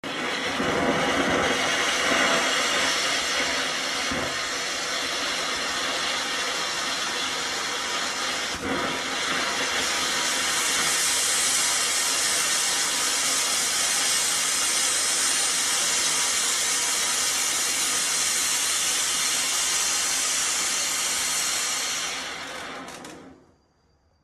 Tiếng Pháo hoa Đám cưới cháy
Thể loại: Tiếng đồ vật
Description: Hiệu ứng âm thanh tiếng đốt pháo đám cưới nổ, pháo điện sân khấu đám cưới cháy, pháo đám cưới thường không nổ mà chỉ phát sáng thành tia và kèm theo có mùi và khói đặc trưng rất đẹp mắt.
tieng-phao-hoa-dam-cuoi-chay-www_tiengdong_com.mp3